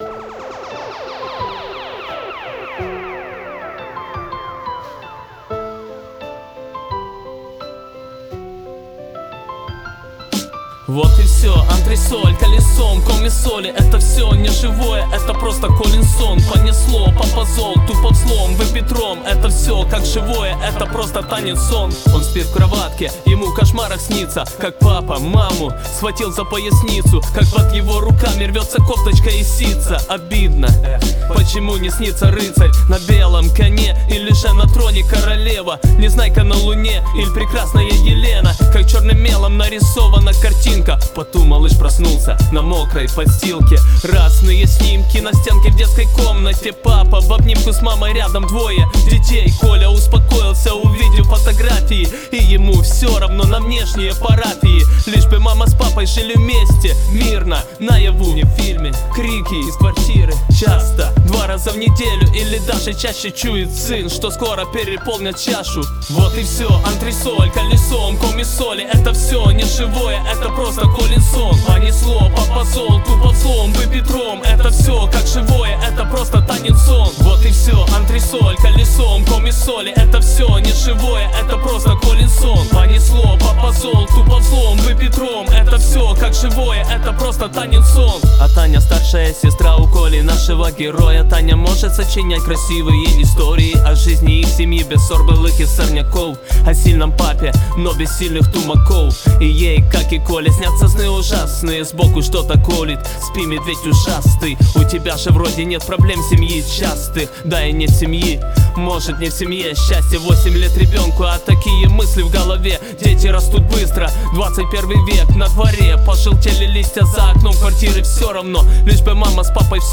Рубрика: Поезія, Авторська пісня
Хороше виконання friends